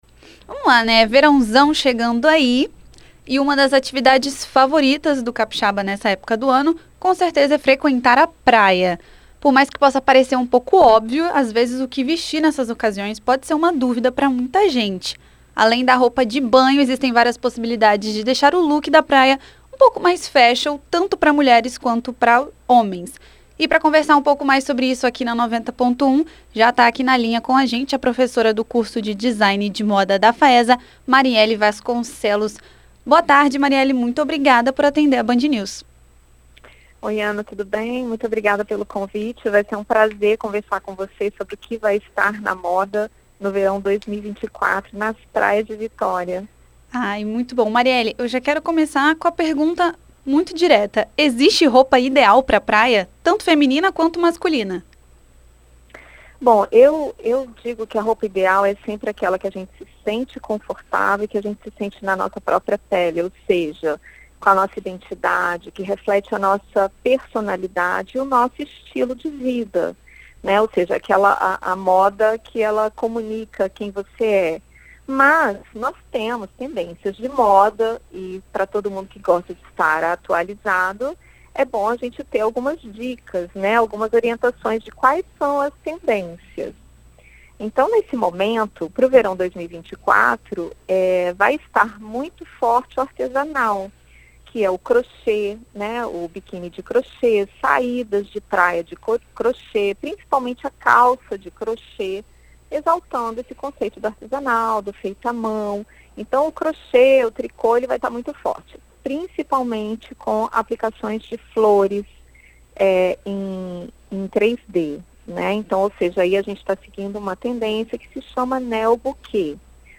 Em entrevista à BandNews FM ES nesta quinta-feira